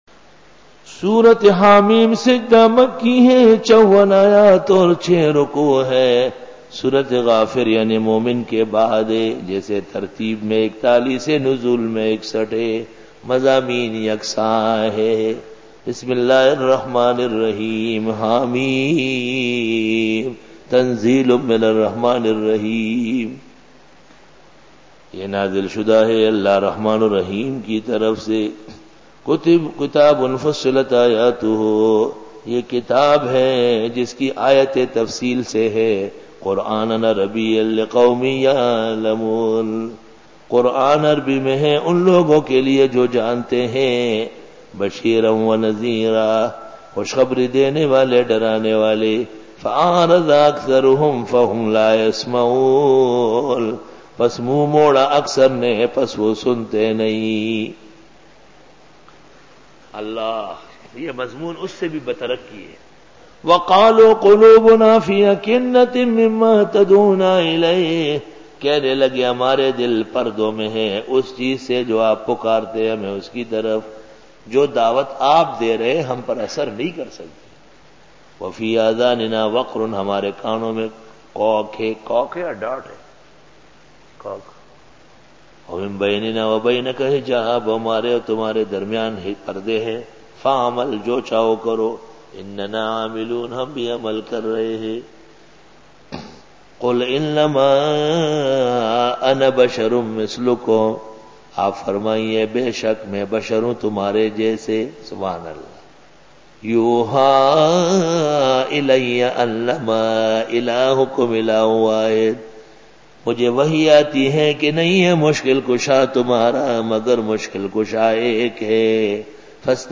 Dora-e-Tafseer 2001